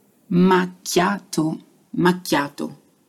Unless you’re in Italy, the way people pronounce macchiato (
Pronuncia_macchiato.mp3